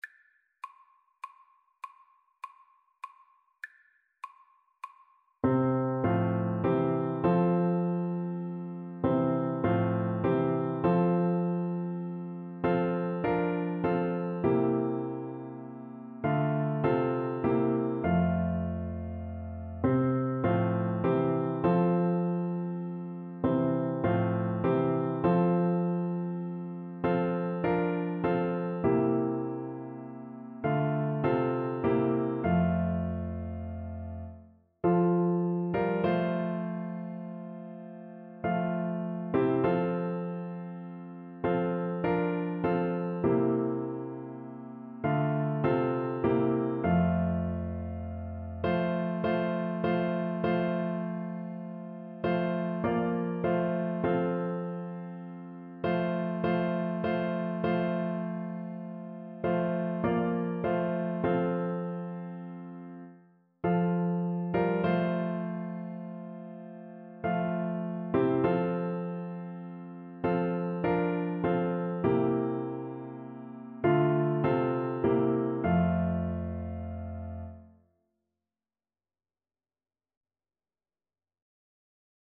Christian
3/2 (View more 3/2 Music)
Classical (View more Classical Flute Music)